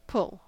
Ääntäminen
IPA : /pʊl/ US : IPA : [pʊl]